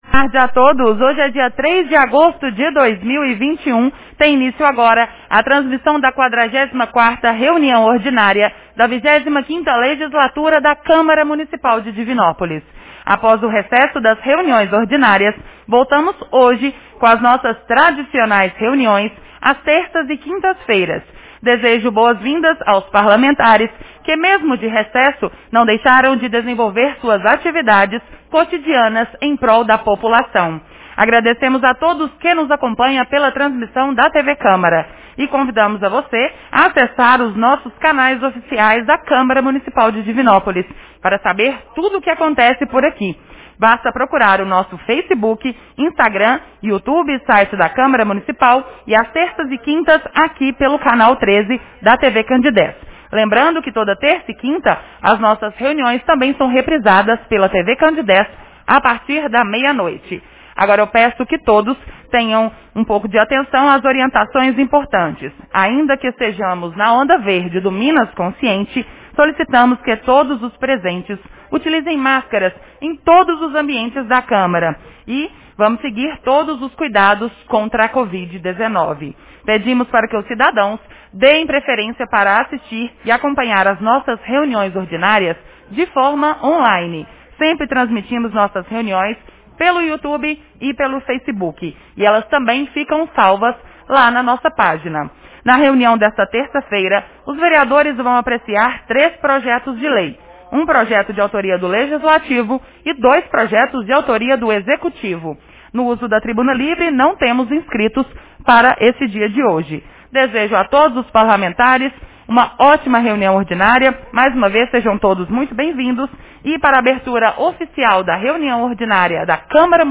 Reunião Ordinária 44 de 03 de agosto 2021